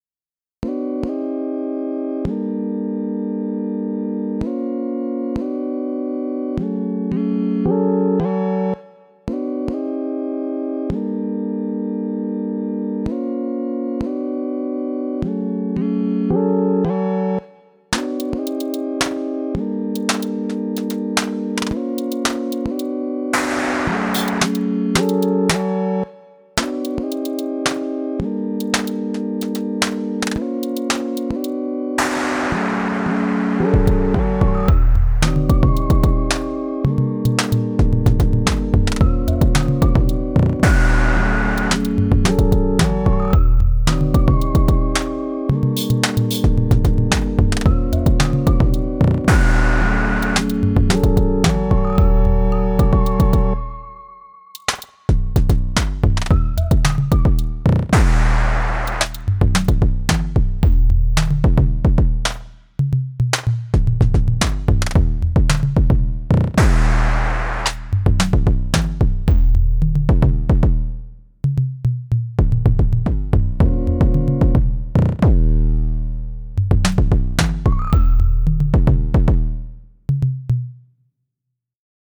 i wanted to upload a couple of older Cycles jams on here, just for fun :slight_smile: